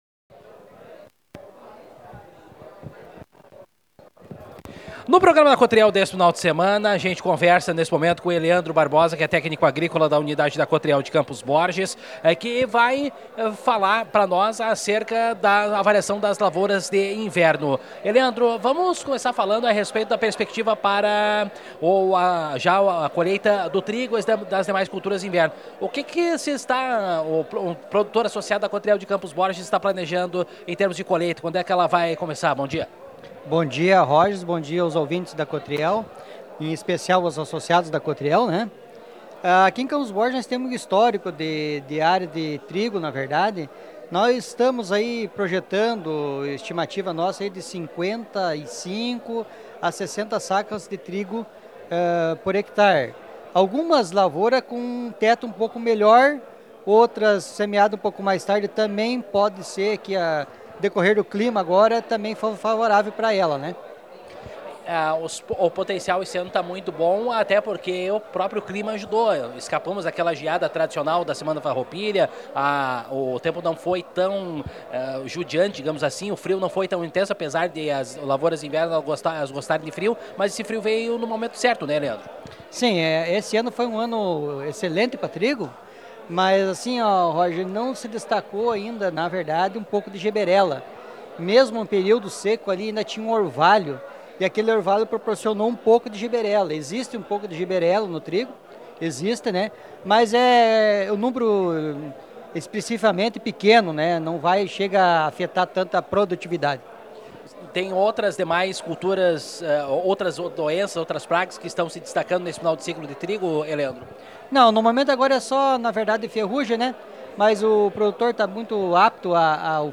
aqui a reportagem.